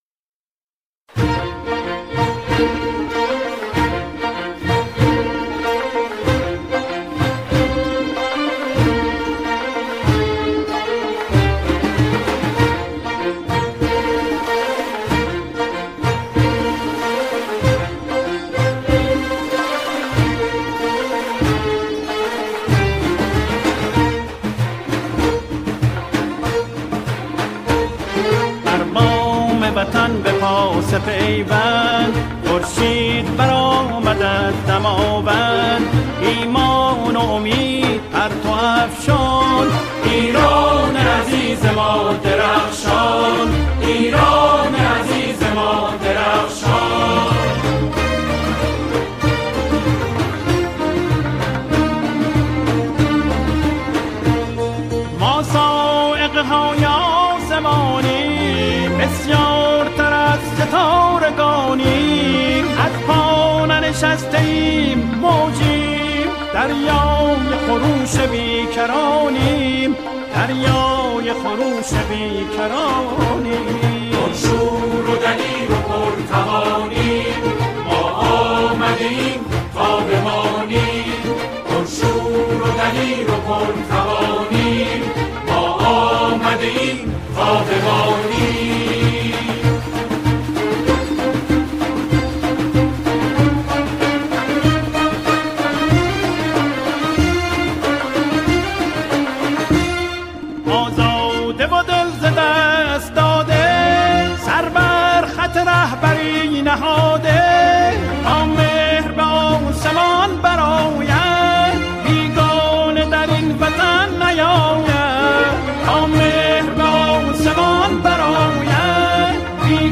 گروه کر